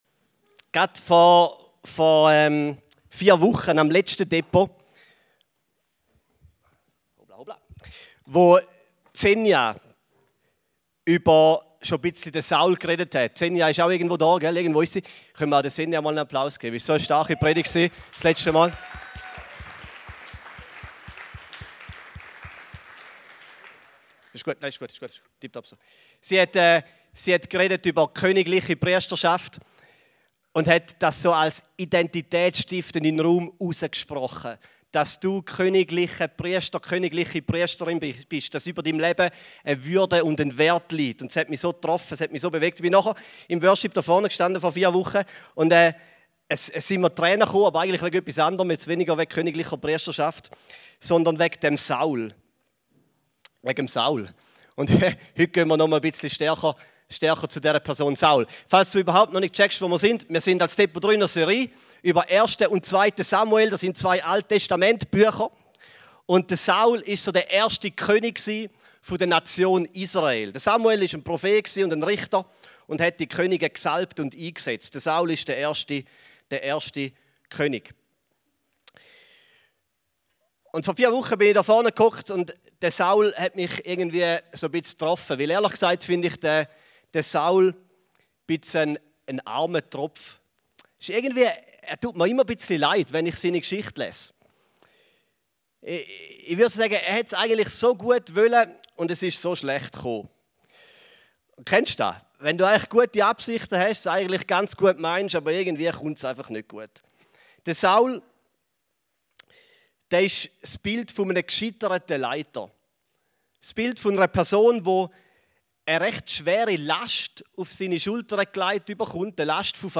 Gottesdienste